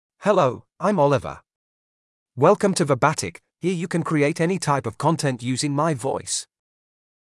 MaleEnglish (United Kingdom)
Oliver is a male AI voice for English (United Kingdom).
Voice sample
Listen to Oliver's male English voice.
Oliver delivers clear pronunciation with authentic United Kingdom English intonation, making your content sound professionally produced.